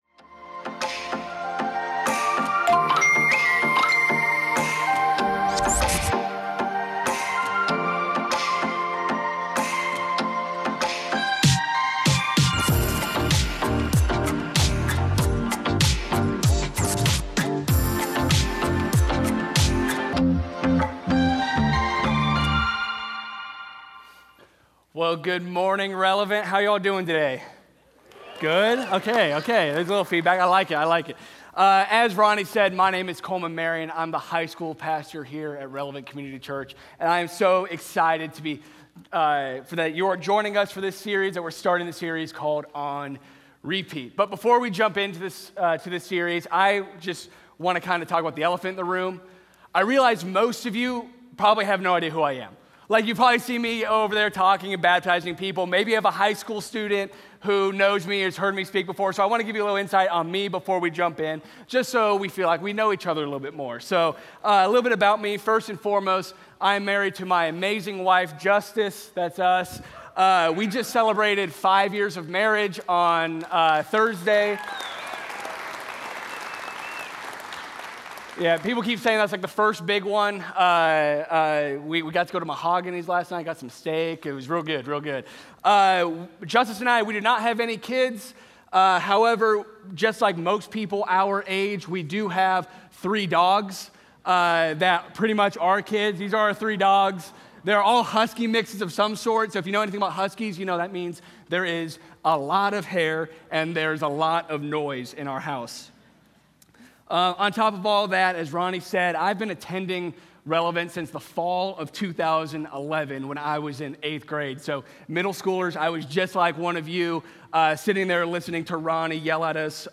Sunday Sermons On Repeat, Week 1: "What’s on Repeat?"